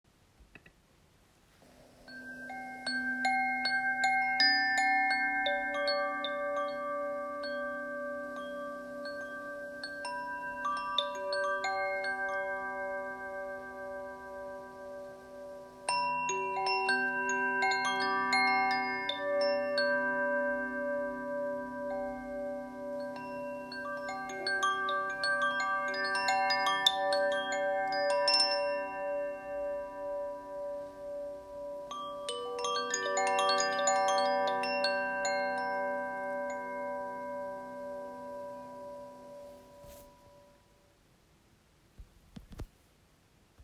Wind chime
• 8 integrated metal rods
• Ignis Tuning: G, B, D, G, B, D, G, A